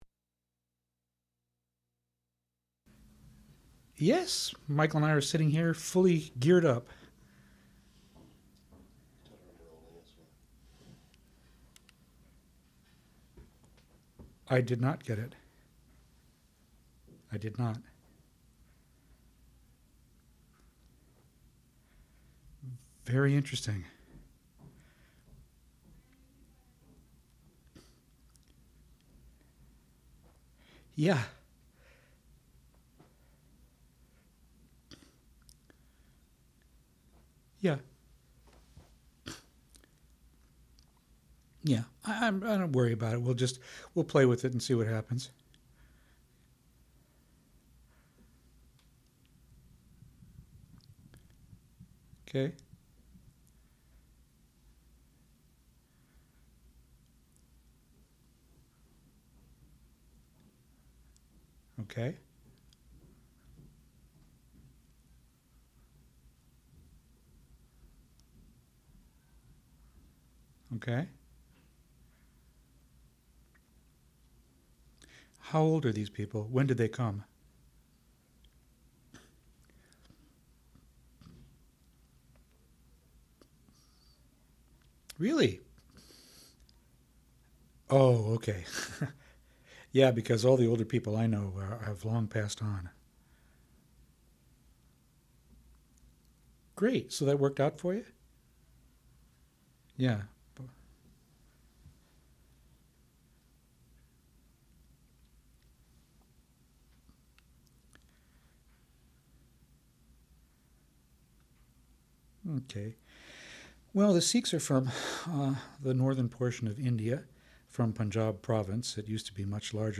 Cultural Anthropologist and South Asian Specialist
Office recording
Interview